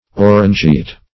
Orangeat \Or`an*geat"\, n.